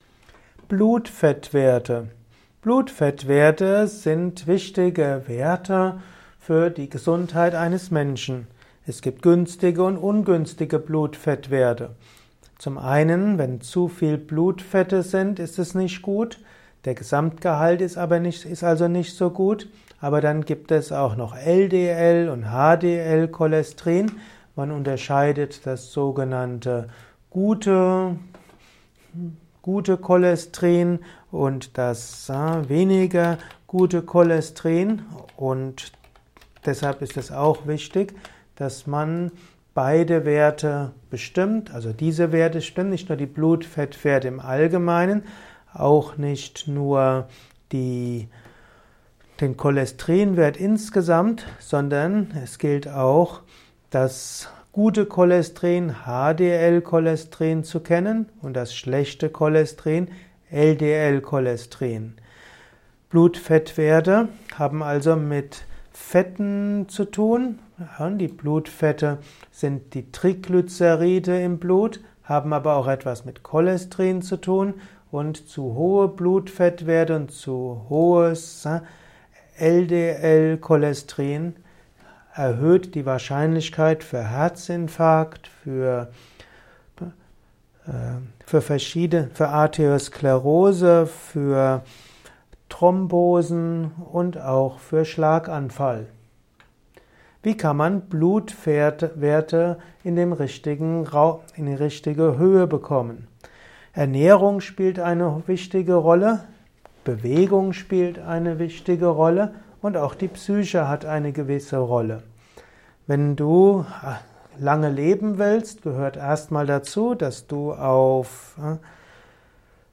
Kompakte Informationen zur Blutfettwerte in diesem Kurzvortrag